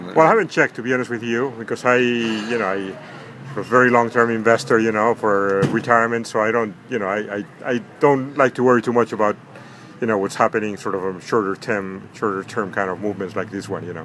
THIS MAN IN THE BOSTON AREA WHO OWNS INDEX FUNDS SAYS HE DID NOT EVEN BOTHER TO CHECK ON HIS INVESTMENTS BECAUSE HIS RETIREMENT HORIZON IS FAR OFF IN THE DISTANCE.